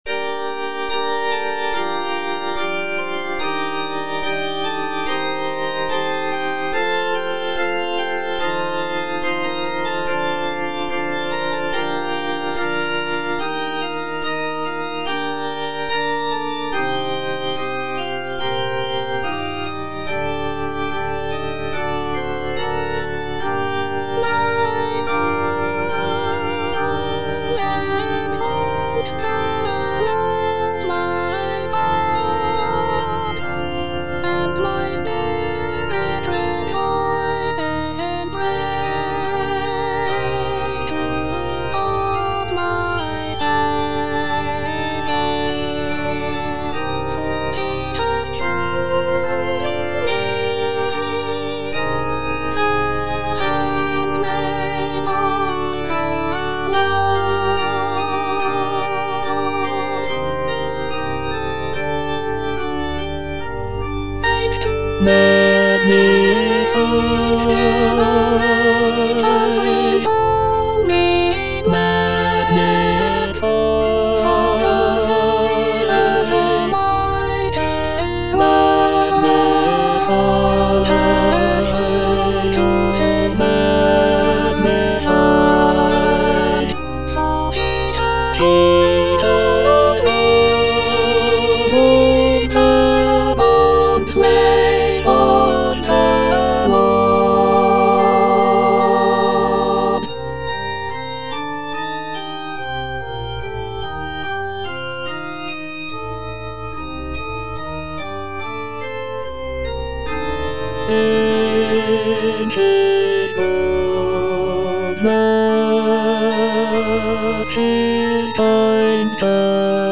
All voices
is a choral piece